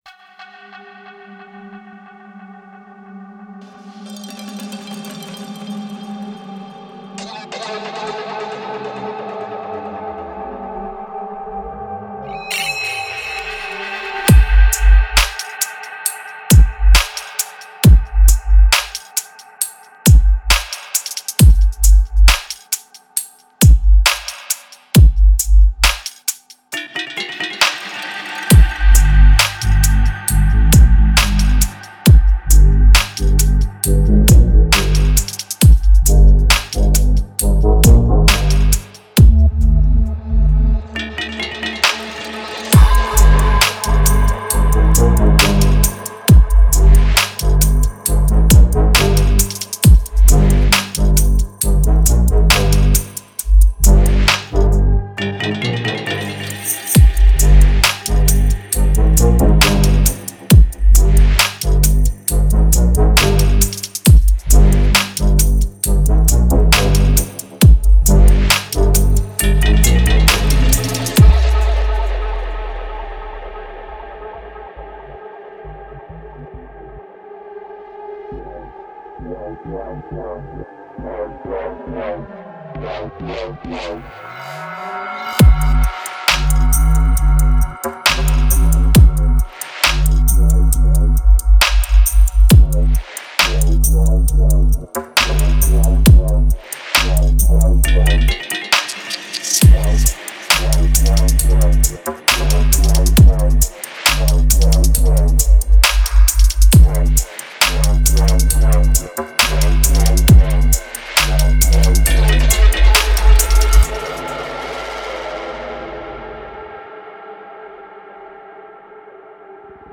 Slowly creeping, haunting beat with deep menacing bassline.